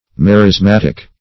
Search Result for " merismatic" : The Collaborative International Dictionary of English v.0.48: Merismatic \Mer`is*mat"ic\, a. [Gr.